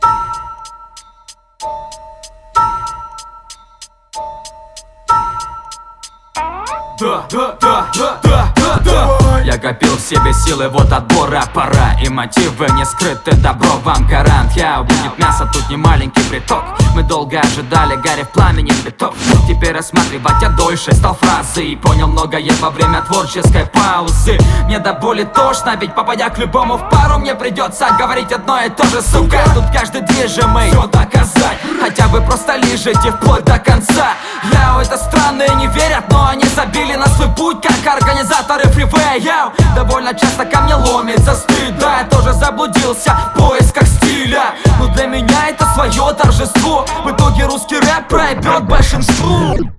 Флоу не ахти, хоть и попытки сделать разнообразно слышу.